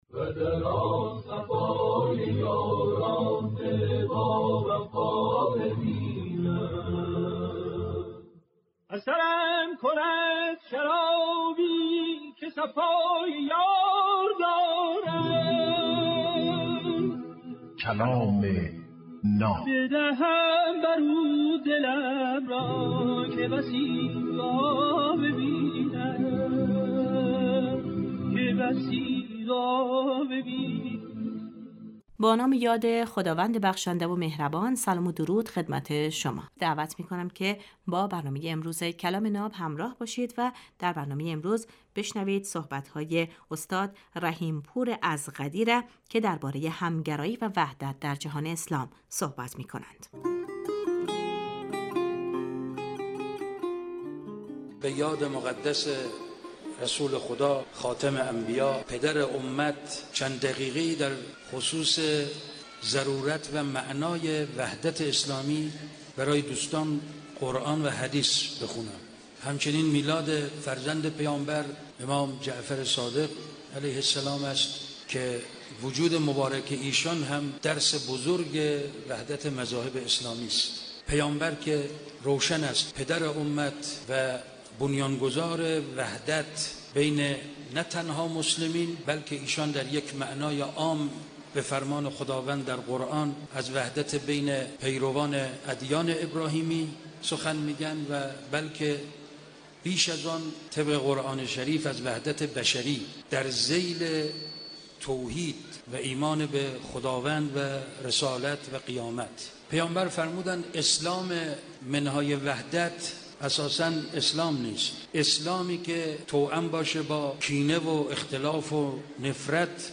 در این برنامه هر روز یک سخنرانی آموزنده کوتاه پخش می شود.